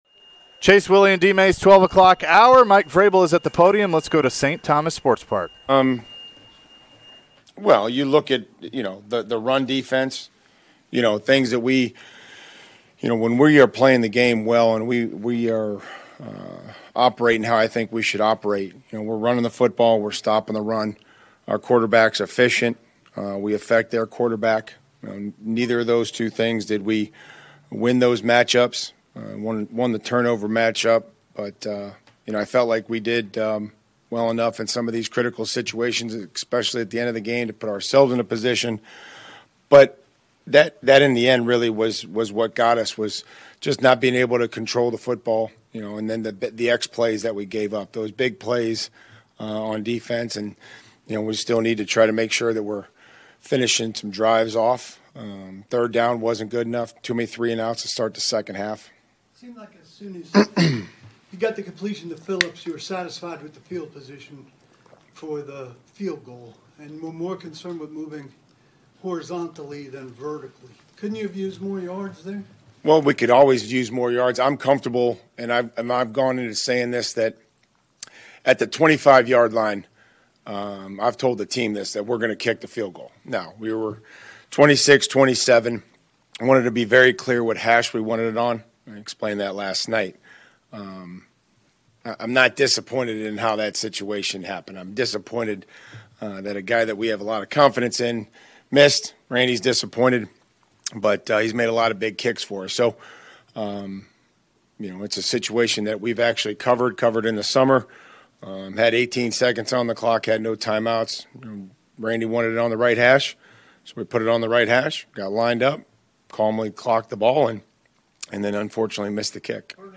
Mike Vrabel presser (9-12-22)